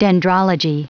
Prononciation du mot dendrology en anglais (fichier audio)